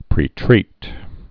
(prē-trēt)